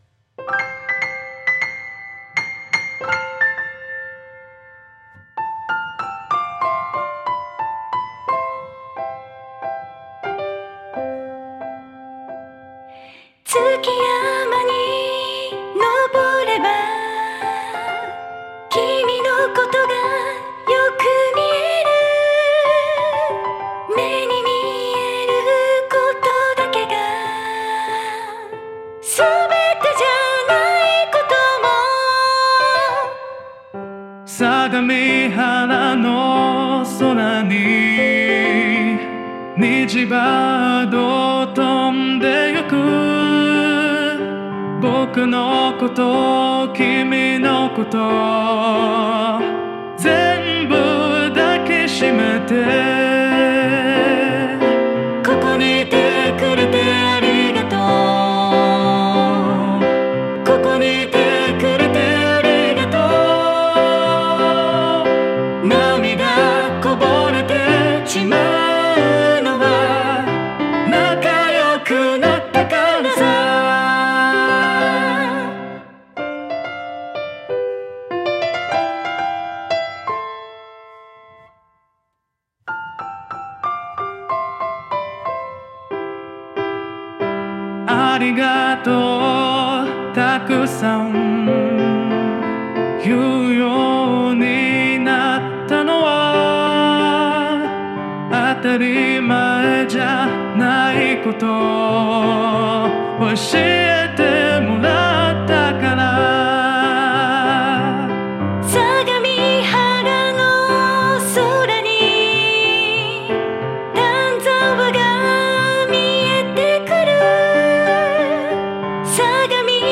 （歌　音声ソフトによるボーカロイドversion）　　視聴・ダウンロード